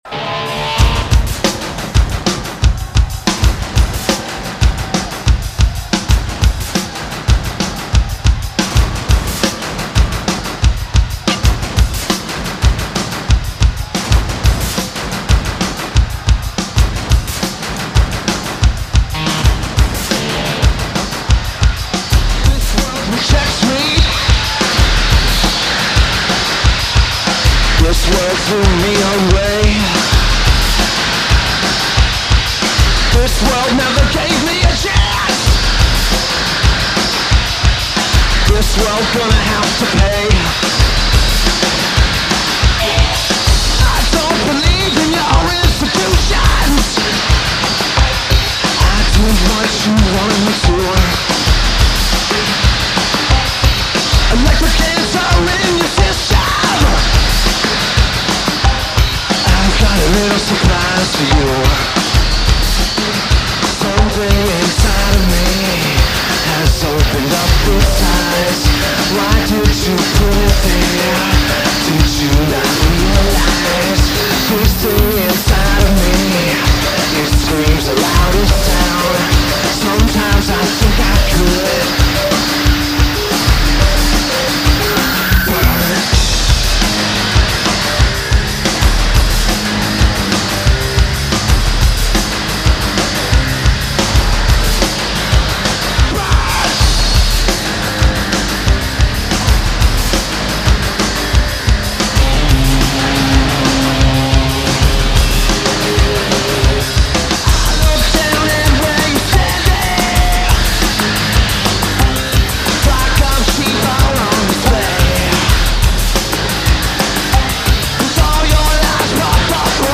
Drums
Bass/Guitar
Keyboards/Programming/Backing Vocals
Vocals/Guitar/Keyboards
Lineage: Audio - PRO (Soundboard)